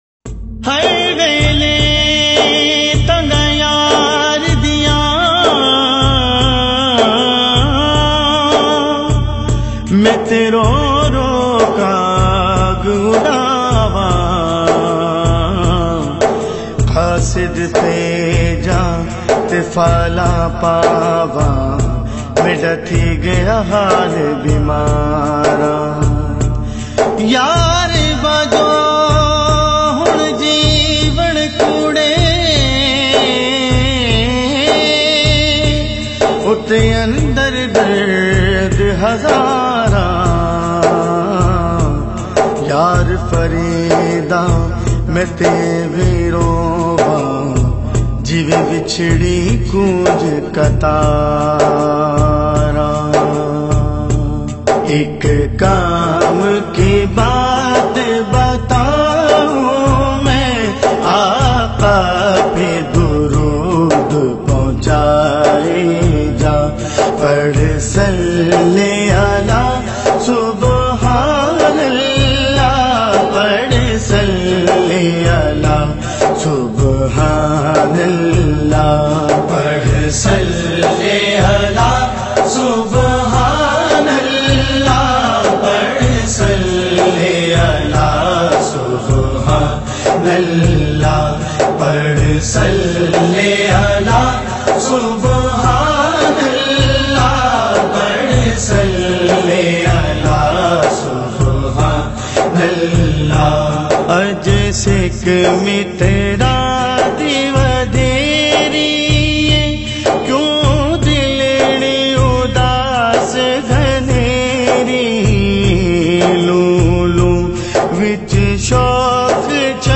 Naat in a Heart-Touching Voice